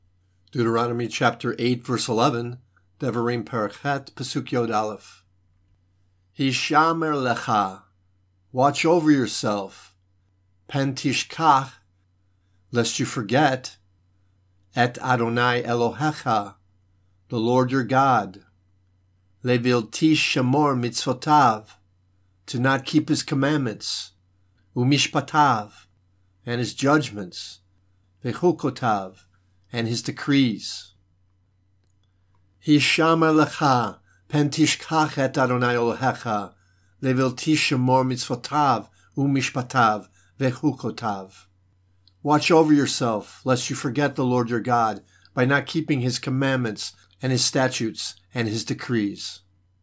Hebrew Lesson
Psalm 137:5 Hebrew reading (click):